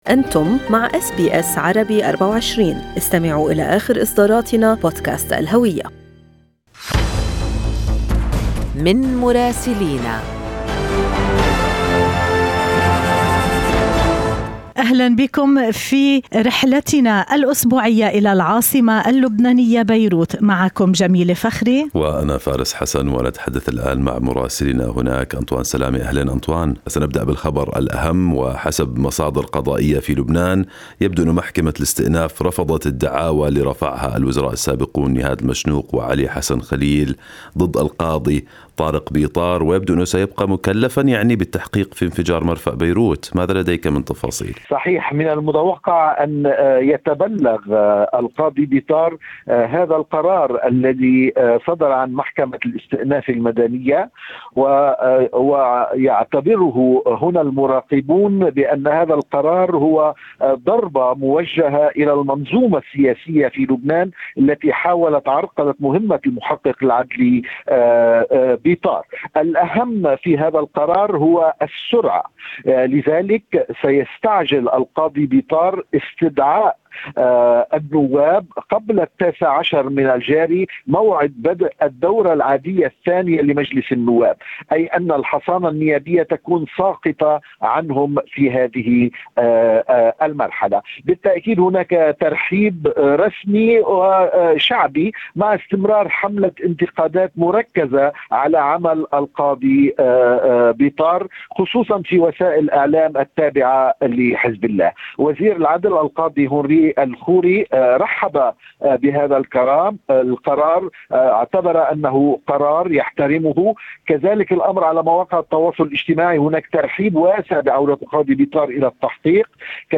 من مراسلينا: أخبار لبنان في أسبوع 5/10/2021